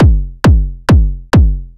Session 04 - Kick.wav